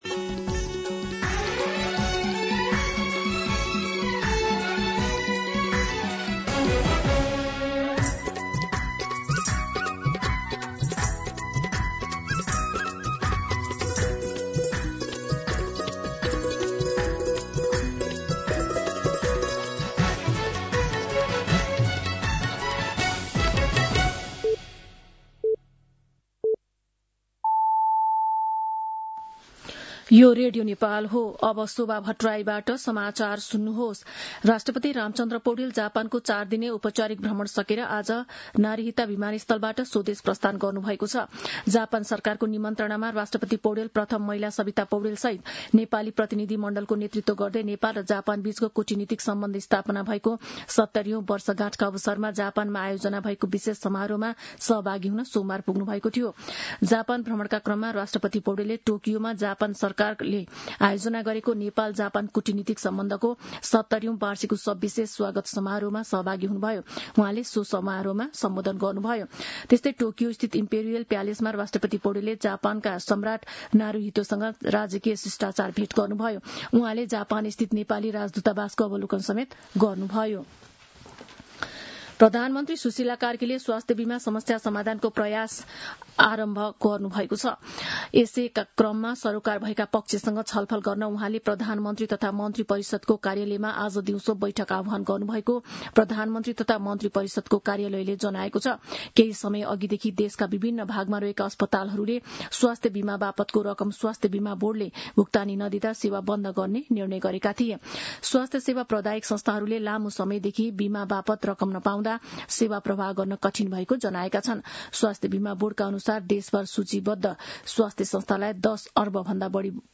मध्यान्ह १२ बजेको नेपाली समाचार : २१ माघ , २०८२
12-pm-Nepali-News.mp3